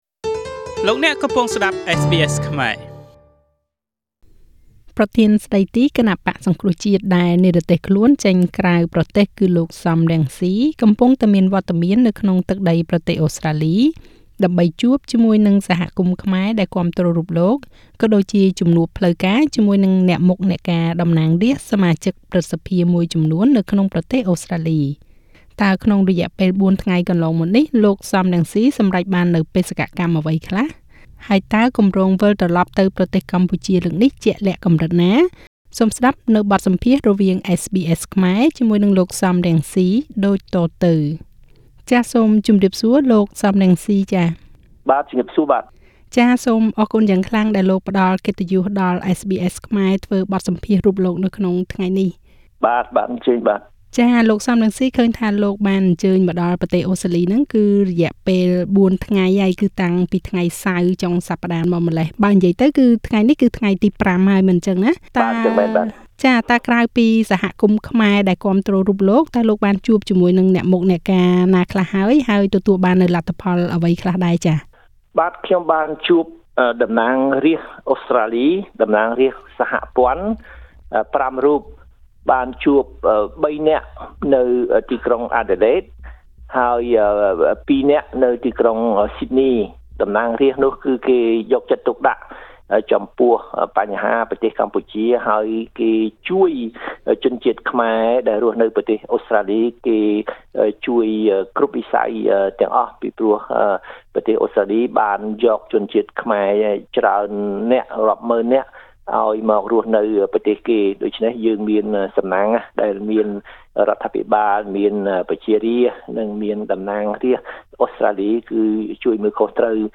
សូមស្តាប់នូវបទសម្ភាសន៍រវាង SBSខ្មែរ និង លោក សម រង្ស៊ី ដូចតទៅ។ Share